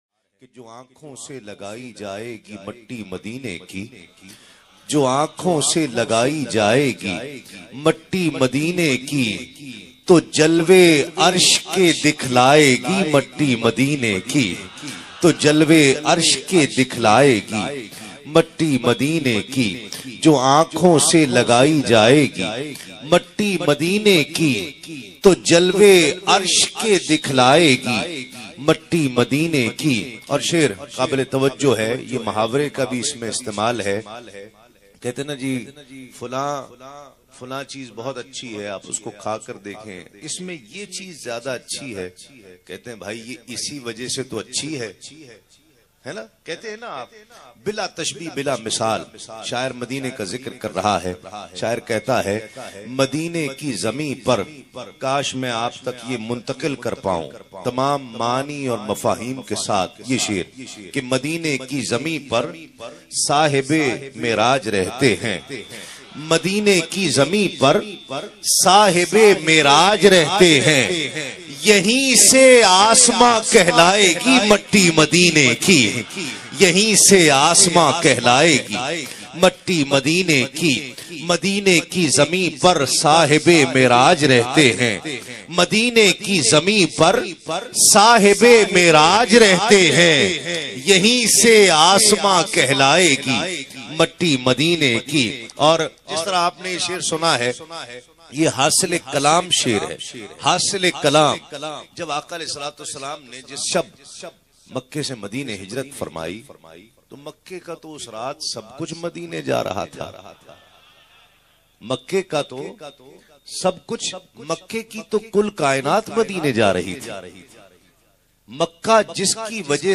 URDU NAAT